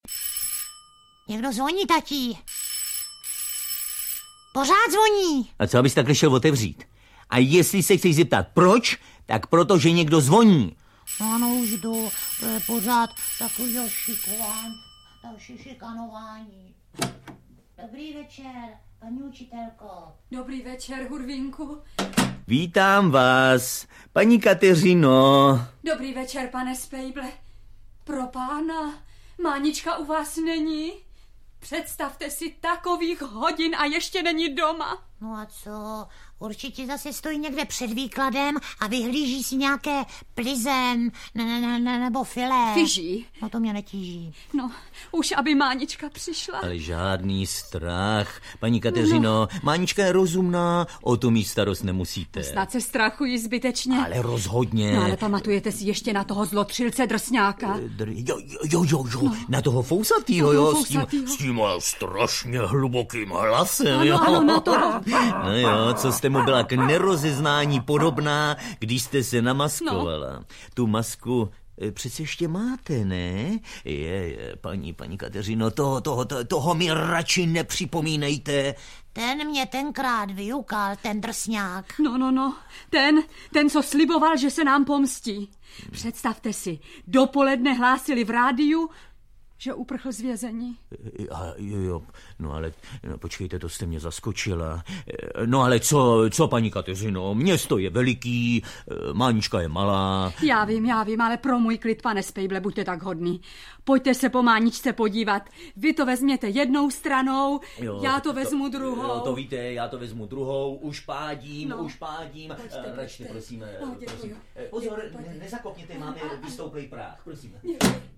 V hlavní roli Mánička audiokniha
Ukázka z knihy